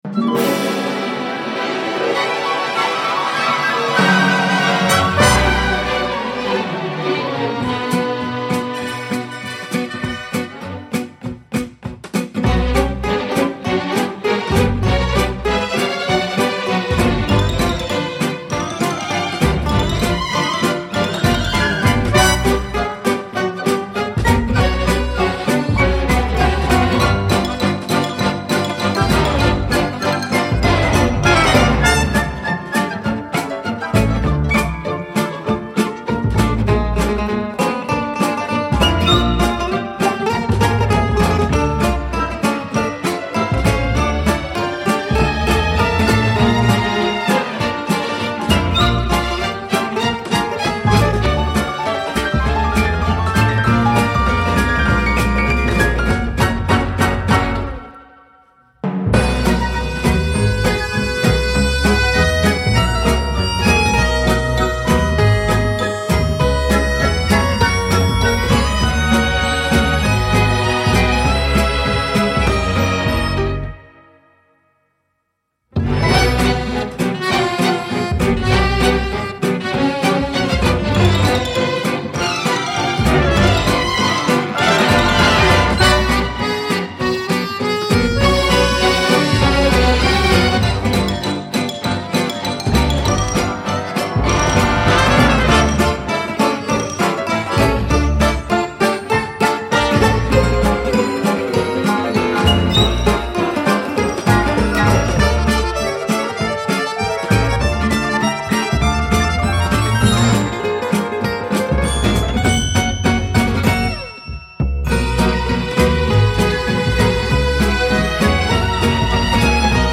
C’est agréable, drôle, référentiel et enlevé.